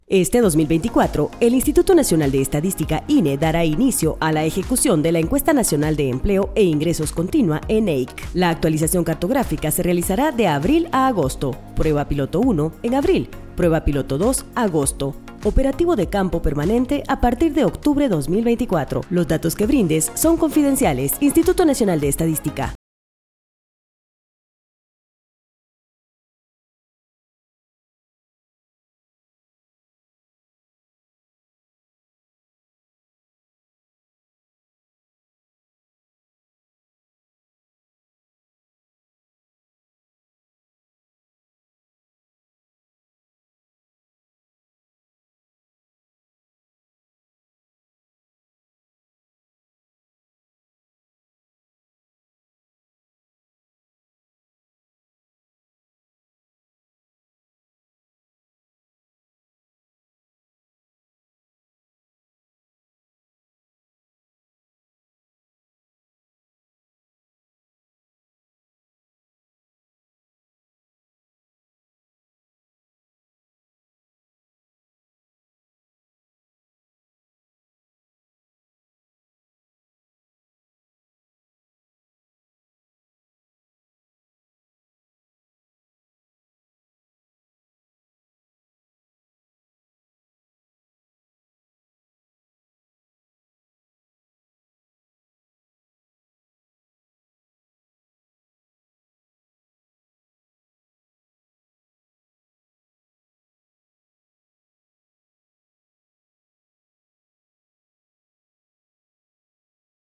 Spot de radio #1